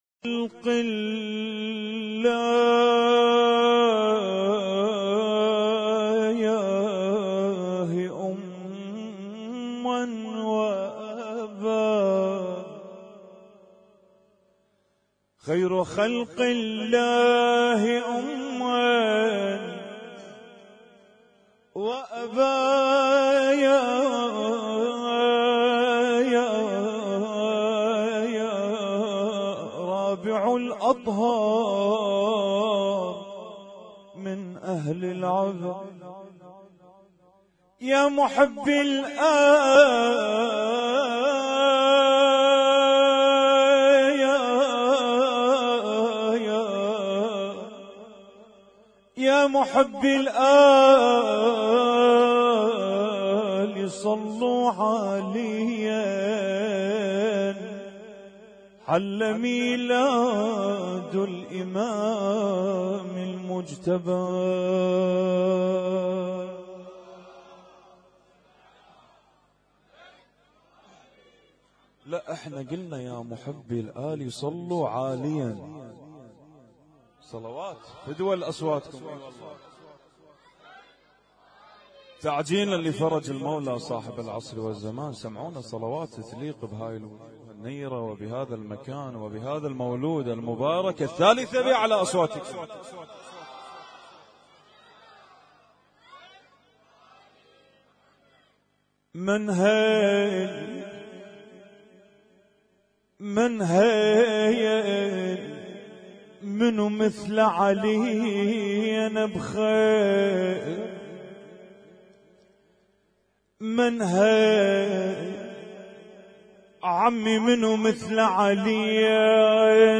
Husainyt Alnoor Rumaithiya Kuwait
اسم النشيد:: ليلة 15 من شهر رمضان 1438- مولد الإمام الحسن المجتبى عليه السلام
القارئ: الرادود
اسم التصنيف: المـكتبة الصــوتيه >> المواليد >> المواليد 1438